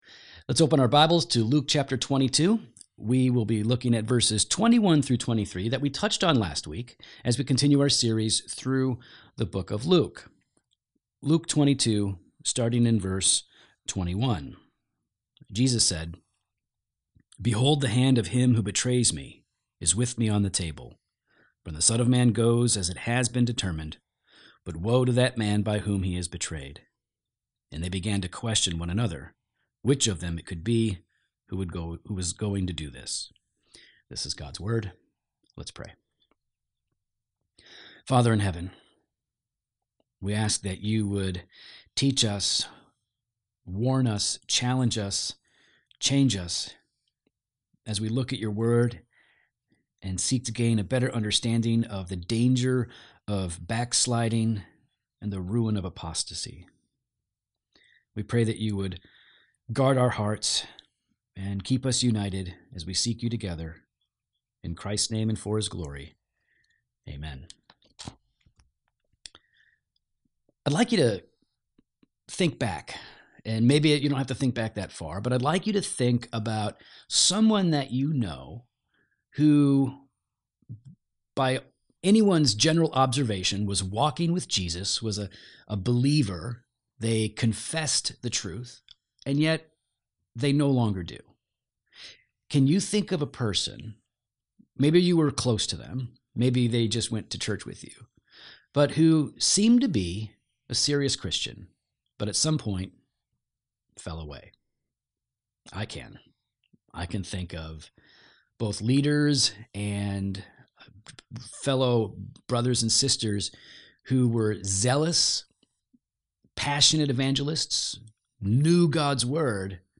— Sermon text: Luke 22:21–23